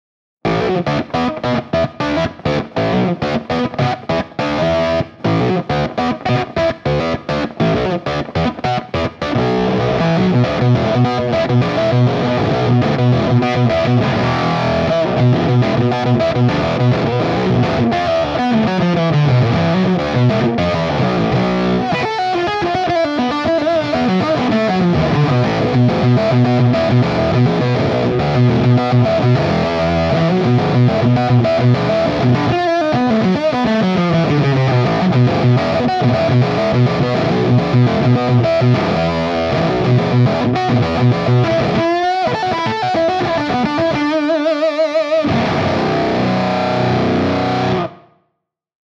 Texas Octave (just octave)
Texas-Octave.wav-just-OCT.mp3